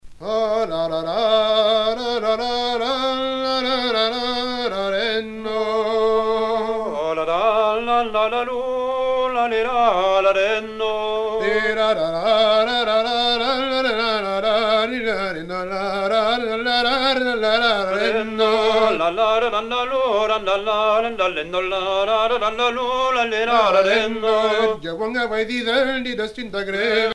Usage d'après l'analyste gestuel : danse